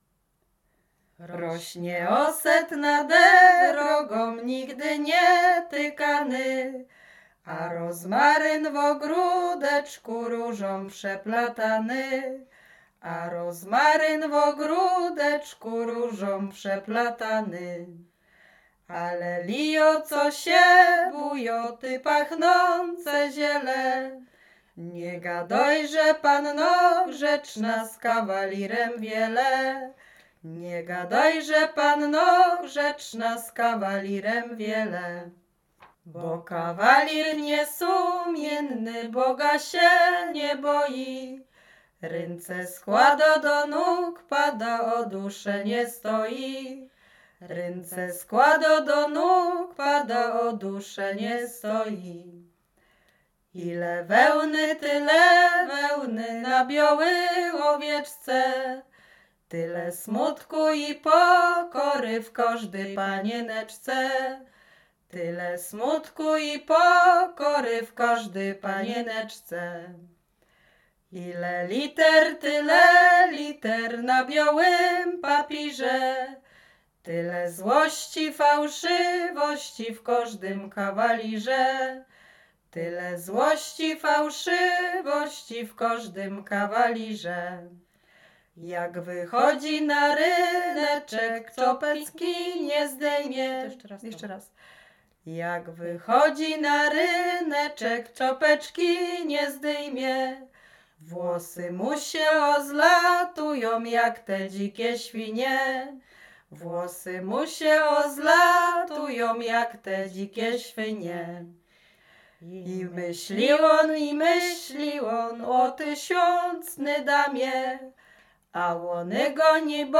Łęczyckie
Melodia i tekst ze wsi Bądków i Warszyce.
kupalskie lato sobótkowe świętojańskie